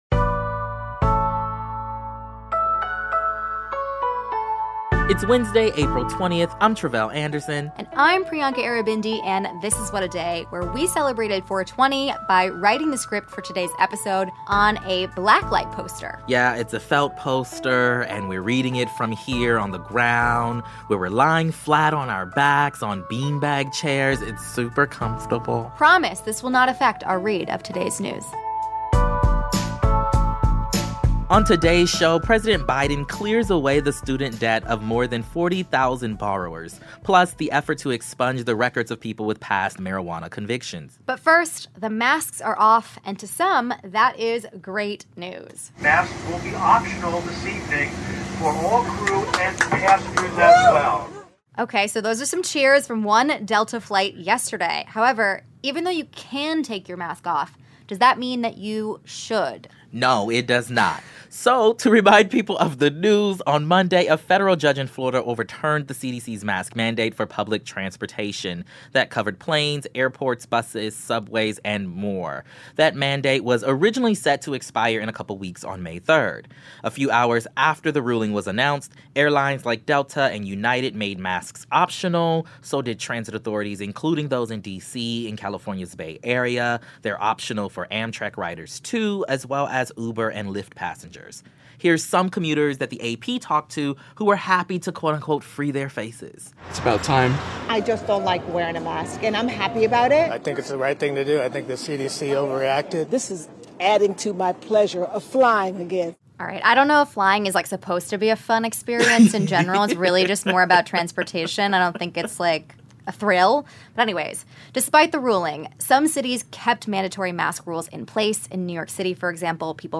And an interview about the effort to decriminalize marijuana, and the disprop